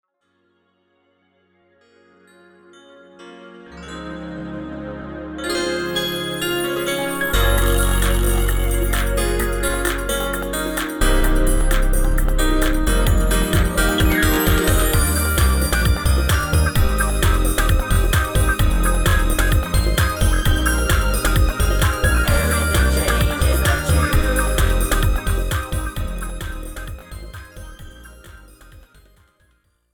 Category Pop